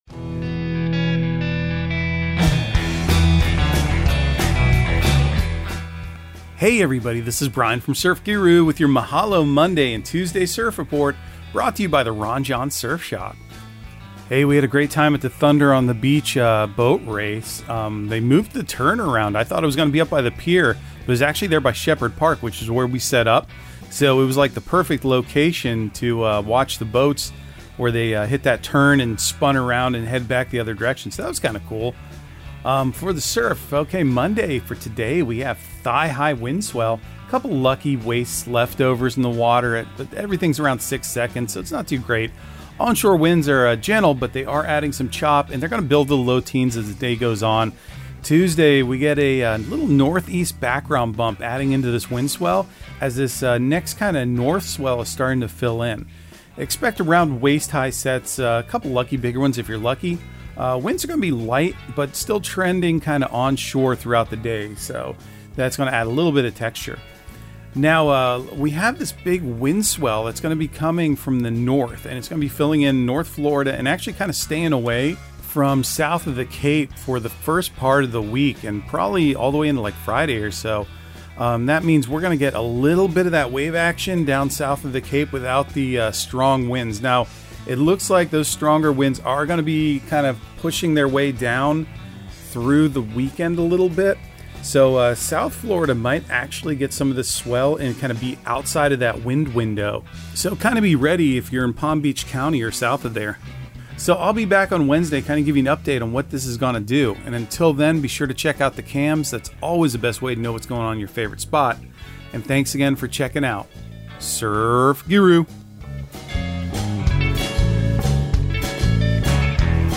Surf Guru Surf Report and Forecast 05/22/2023 Audio surf report and surf forecast on May 22 for Central Florida and the Southeast.